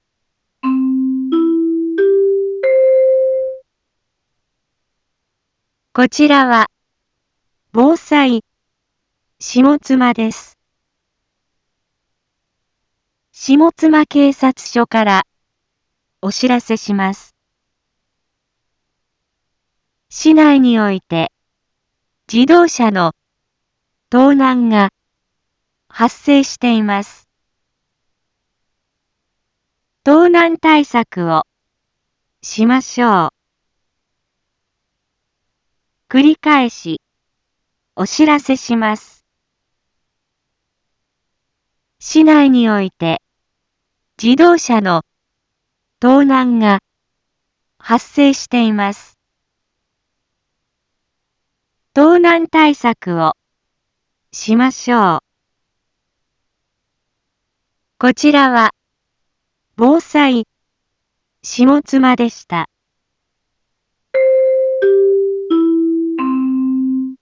一般放送情報
Back Home 一般放送情報 音声放送 再生 一般放送情報 登録日時：2021-12-16 12:31:11 タイトル：自動車盗難への警戒について インフォメーション：こちらは、防災下妻です。